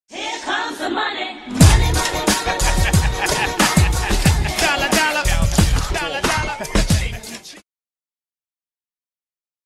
money-money-money-sound-effect-hd_dUbkna4.mp3